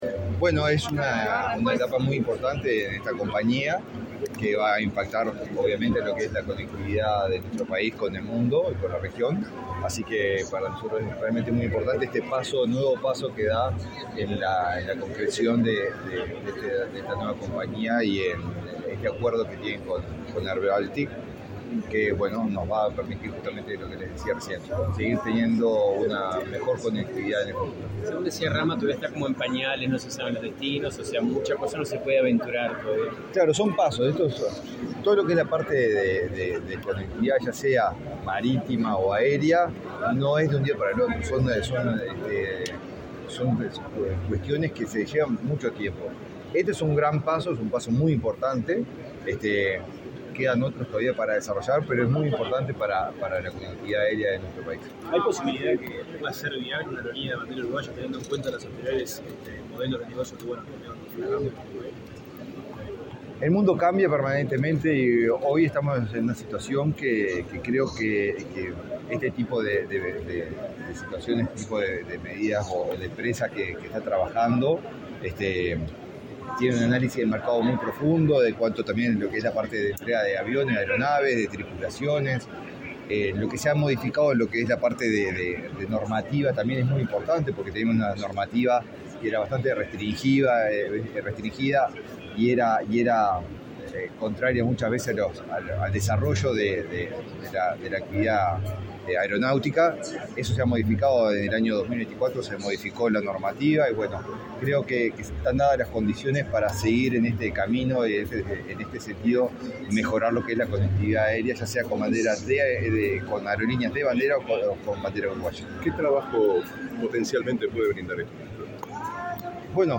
Declaraciones a la prensa del ministro de Turismo, Eduardo Sanguinetti
Declaraciones a la prensa del ministro de Turismo, Eduardo Sanguinetti 29/01/2025 Compartir Facebook X Copiar enlace WhatsApp LinkedIn El ministro de Turismo, Eduardo Sanguinetti, participó, este 28 de enero, en la firma de un acuerdo entre SUA Líneas Aéreas, la nueva aerolínea de bandera uruguaya, y Air Baltic. Tras el evento, el jerarca realizó declaraciones a la prensa.